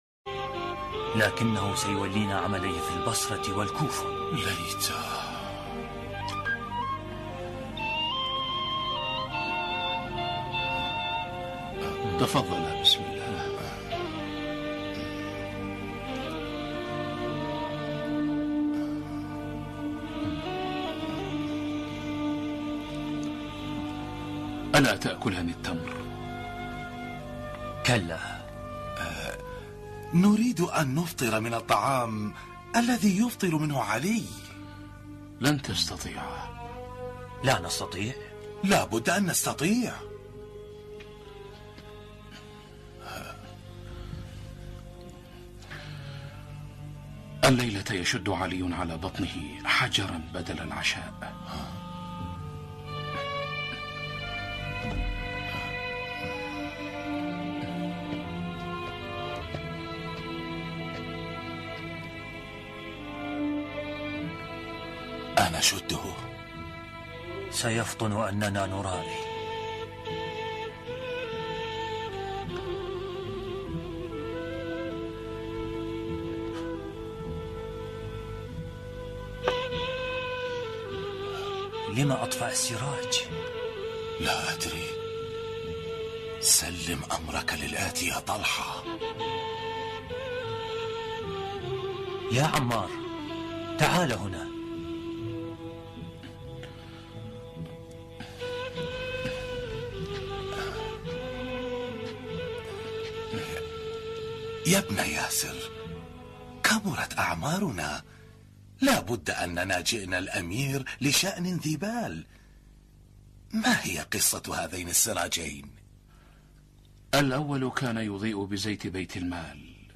ملف صوتی زهد وعدالة الإمام علي سلام الله عليه بصوت الشيخ الدكتور أحمد الوائلي